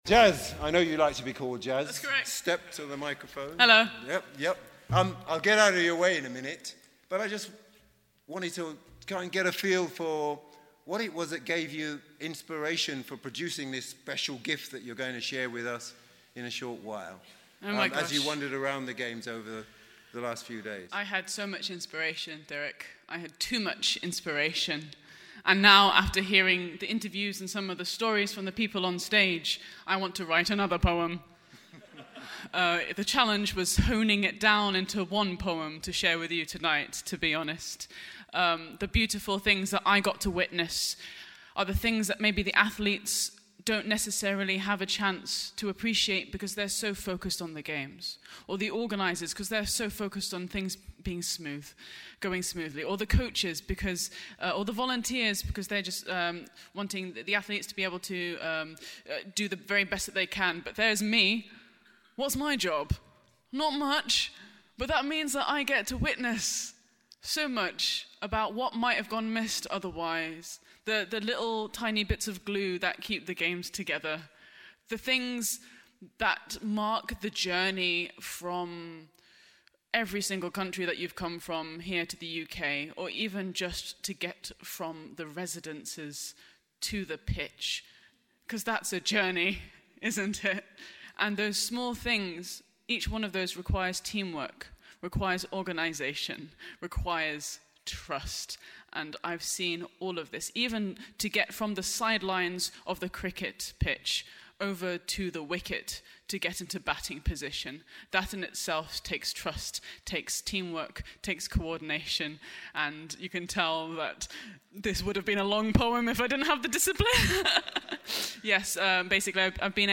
IBSA WORLD GAMES 2023 - Closing Ceremony